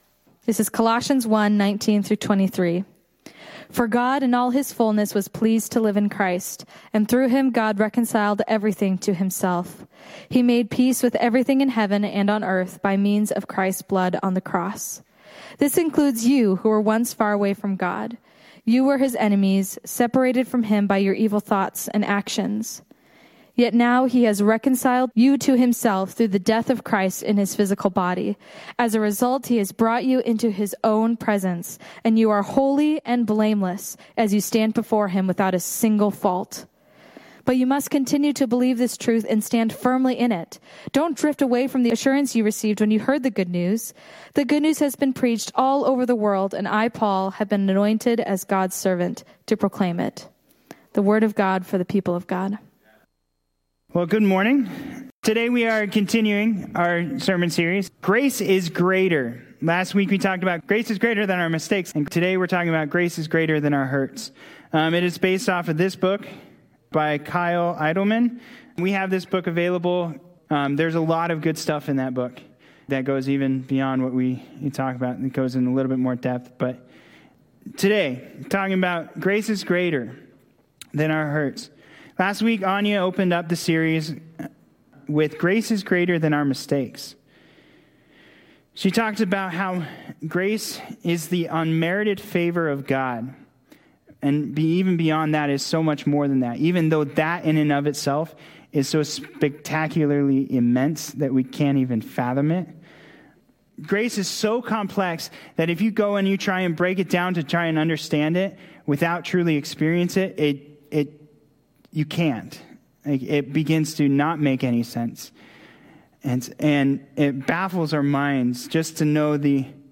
2021 Grace is Greater than our Hurts Preacher